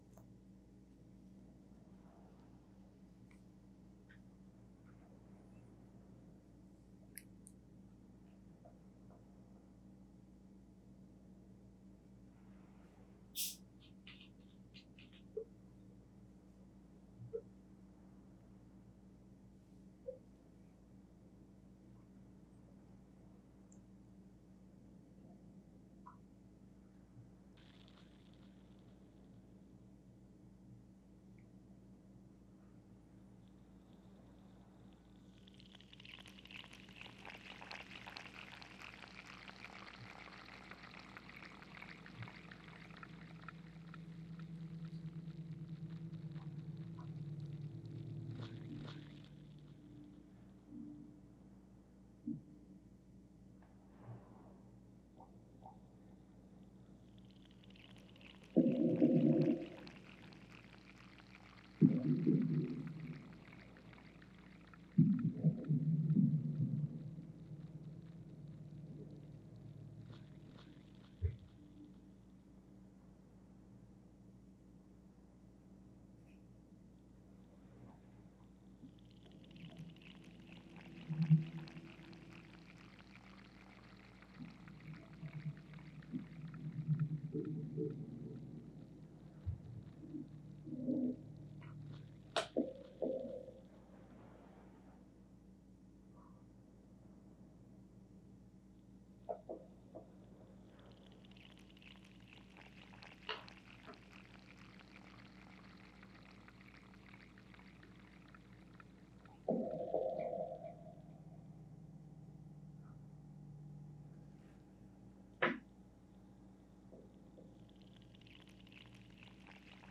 mediocre paintings from summer :D 05/23/2025 casted wax sculpture with beeswax, live loop with water and hydrophone 05/10/2025 : making the class participate in a musical performance with found object and make shift instruments and instrument-intruments 03/07/2025
wax-sculpture---hydrophone-live-loop.m4a